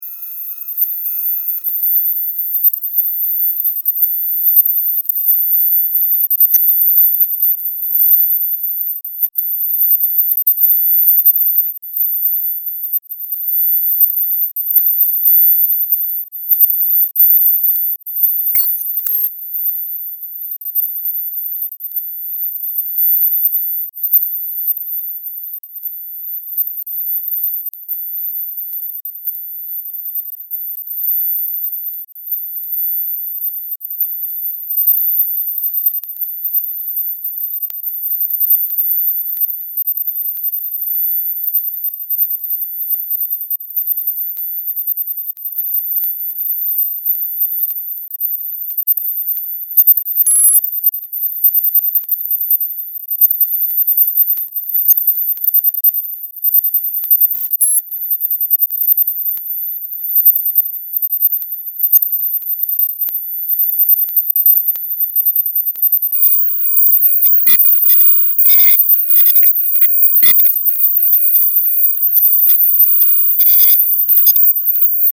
Audio musical
sound art
Soundscapes
Noise music